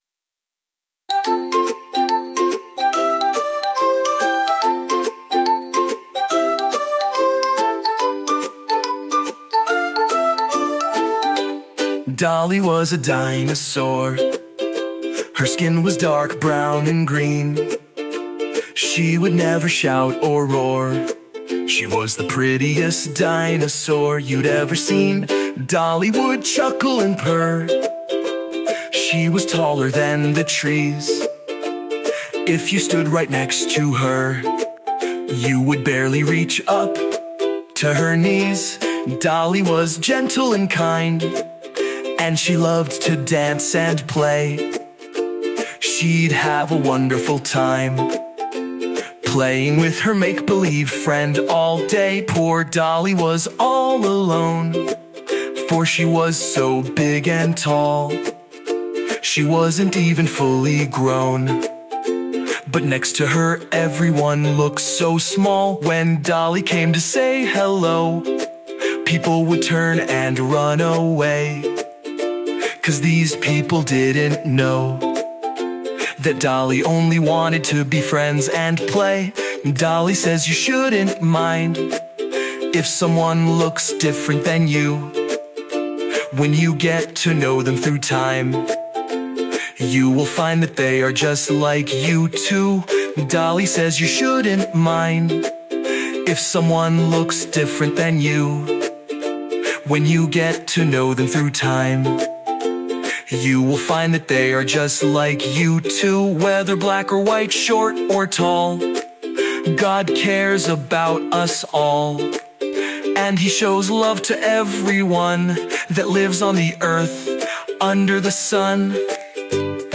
a heartwarming, fun, and educational song